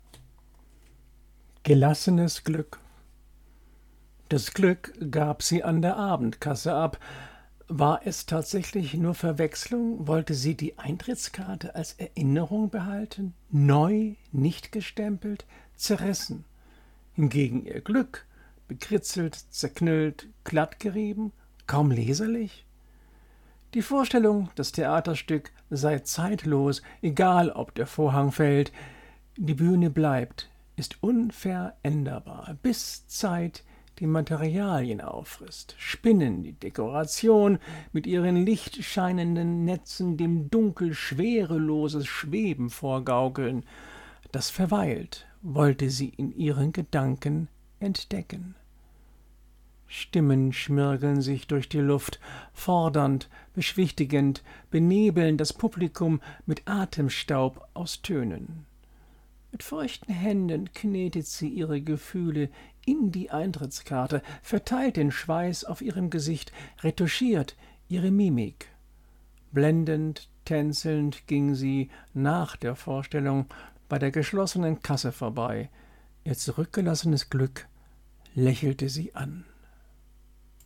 Gedichte – rezitiert
Insofern möchte ich hierbei so manche Gedichte für euch rezitieren.